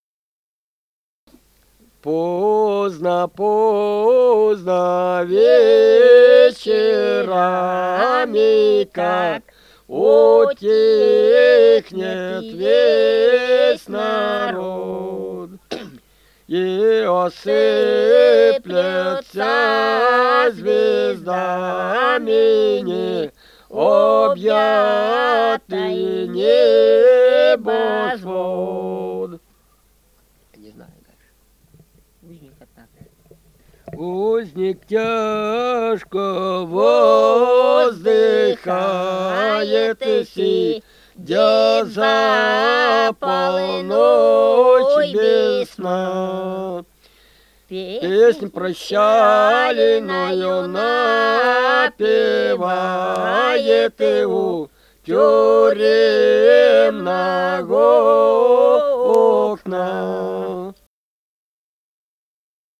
Русские песни Алтайского Беловодья 2 «Поздно, поздно вечерами», стих узника-невольника.
Республика Алтай, Усть-Коксинский район, с. Тихонькая, июнь 1980.